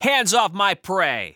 File:Falco voice sample SSBU EN.oga
Falco_voice_sample_SSBU_EN.oga.mp3